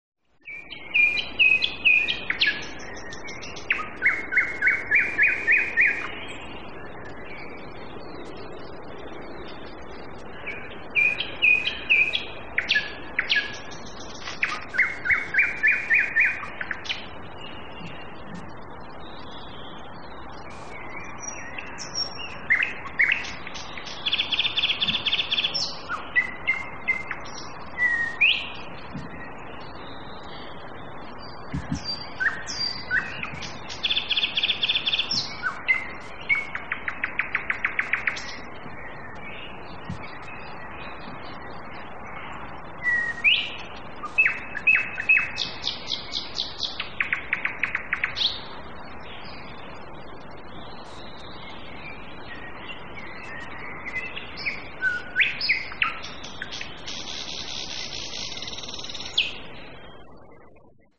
И словно в благодарность парку, в той его части, что густо заросла ивняком, в вечернем сумерке запели соловьи, славя приход Весны…
Хотите послушать как поет соловей?